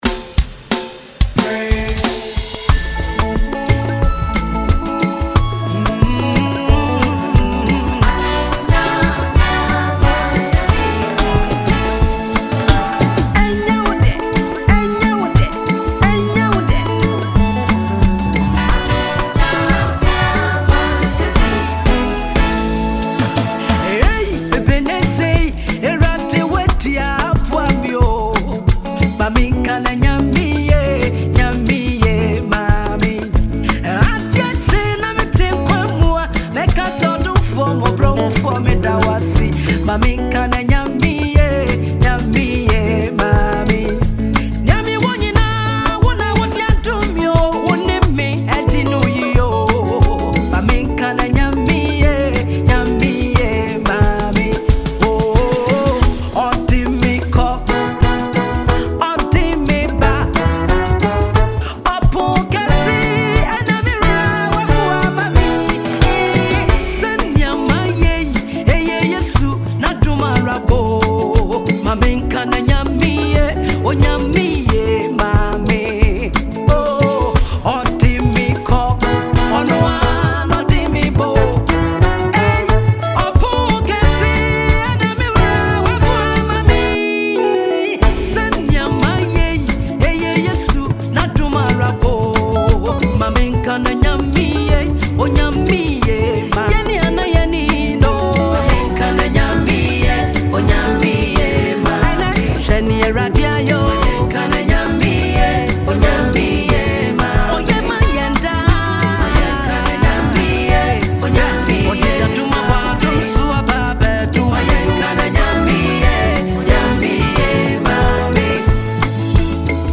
Award-winning gospel singer